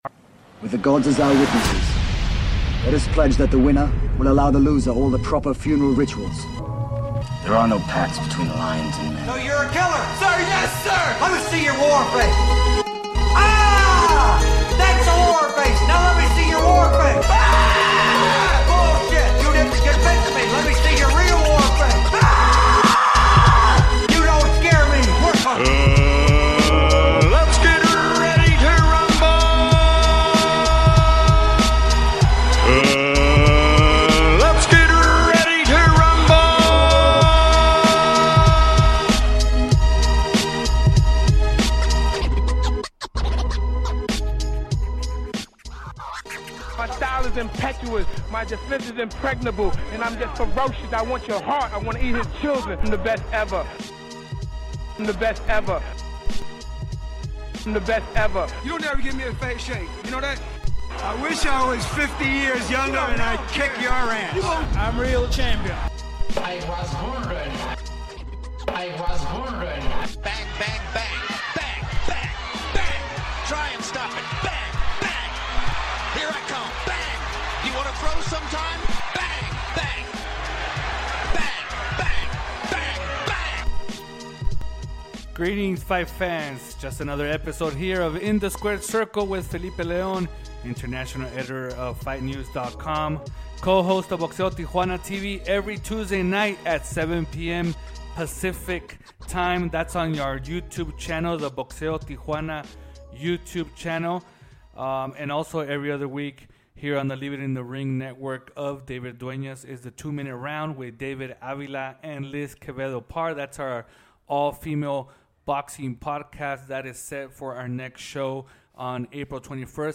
passes the latest news in the sport with a fast pace style of 30 minutes or less